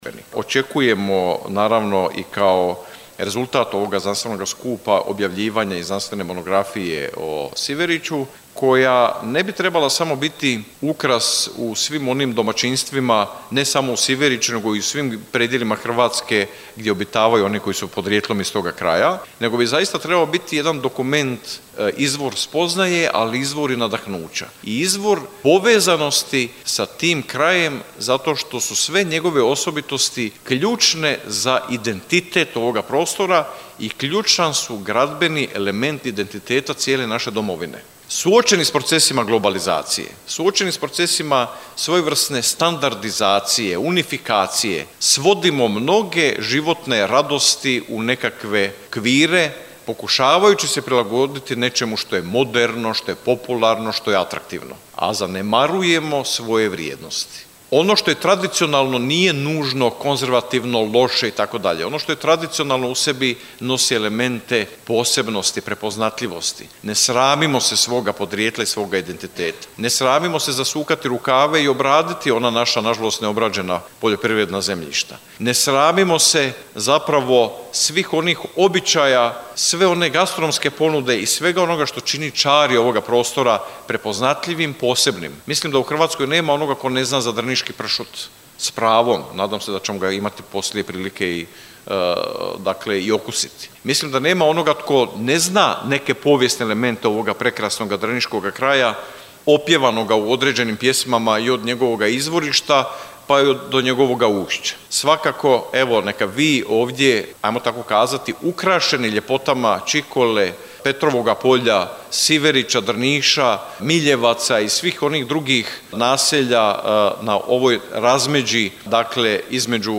Znanstveni skup o Siveriću – pogled u povijest s ciljem jasnije budućnosti